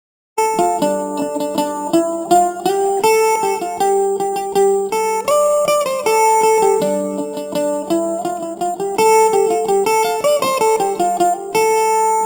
The third has been processed with a multiplication factor of 4
The “distortion” can be clearly heard as additional high frequencies. It certainly makes the sound “brighter” but with something of a metallic sound to it.